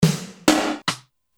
snare drum (x99)
snare.mp3